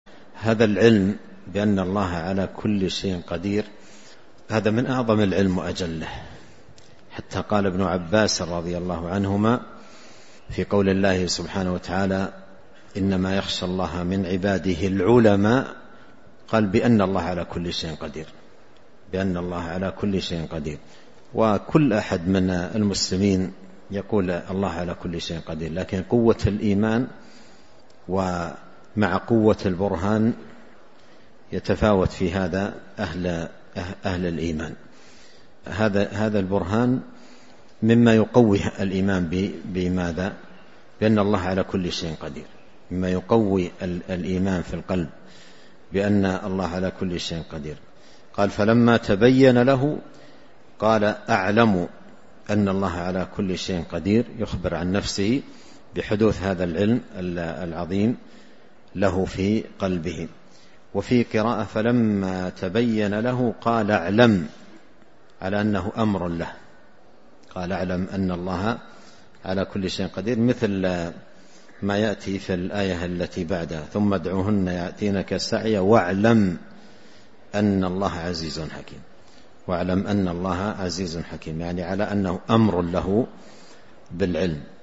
📚 من شرح تفسير السعدي | الدرس (138).